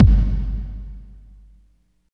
30.03 KICK.wav